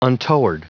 Prononciation du mot untoward en anglais (fichier audio)
Prononciation du mot : untoward